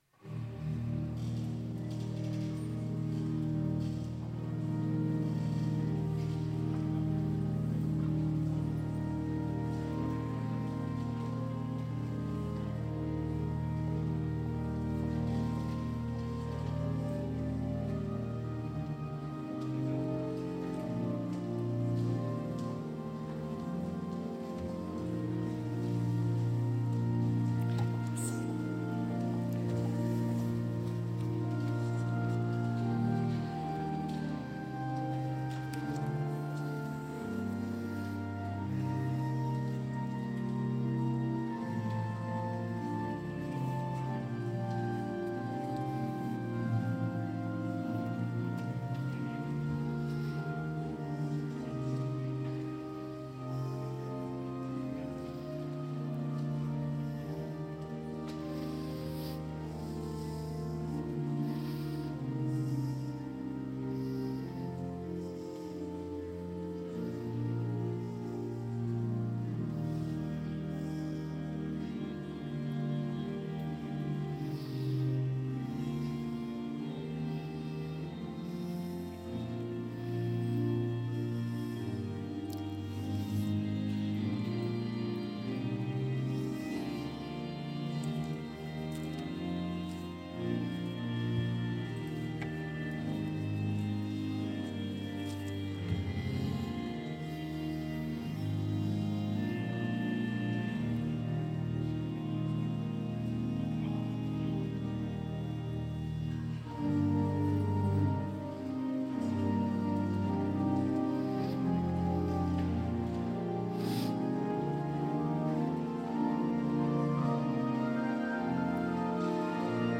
Culte de Noël à l’Oratoire du Louvre